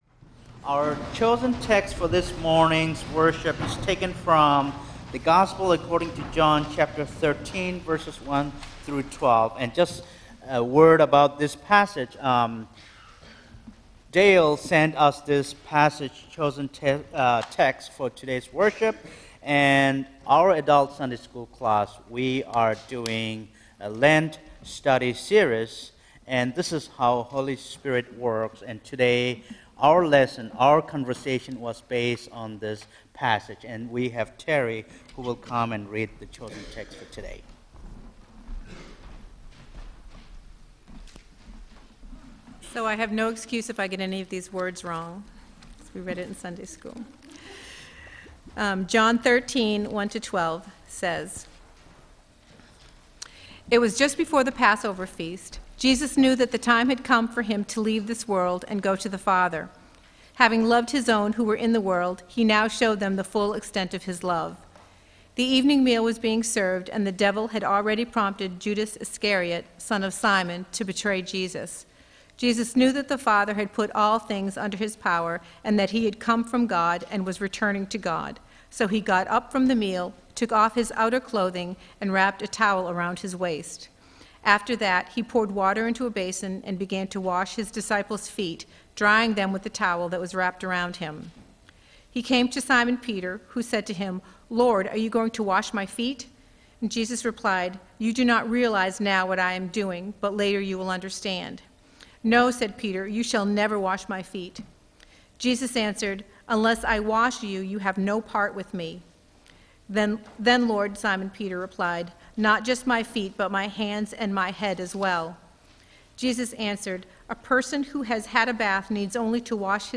Audio Recording Of Feb. 28 Worship Service – Now Available
The audio recording of our latest Worship Service is now available.